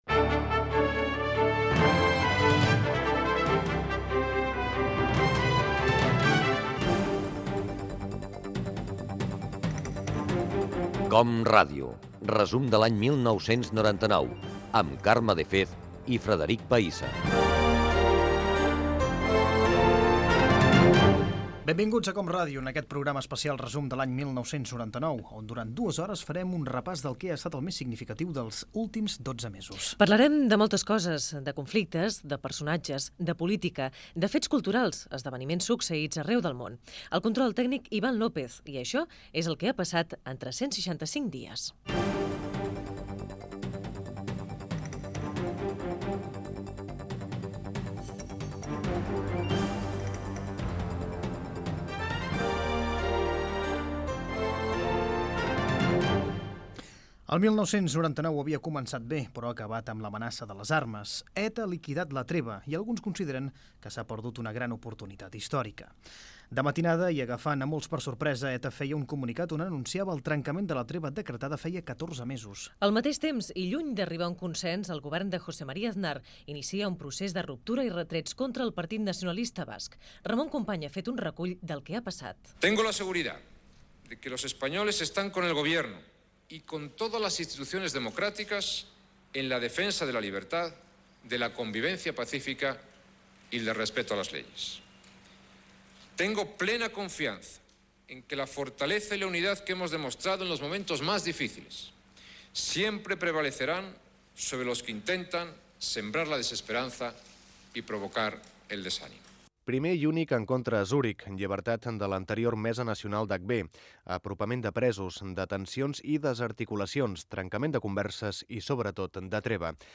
Careta del programa, presentació, resum del més destacat ETA havia acabat una treva.
Informatiu